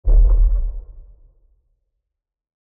دانلود افکت صوتی بوم تامپ بزرگ از راه دور
یک گزینه عالی برای هر پروژه ای است که به انتقال و حرکت و جنبه های دیگر مانند Boom Thump Big، Distant Dull Impact و Hollow Rumbling نیاز دارد.